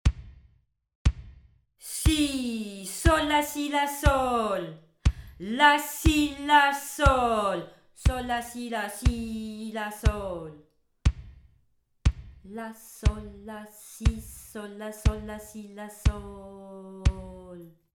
26 - Rythme frappé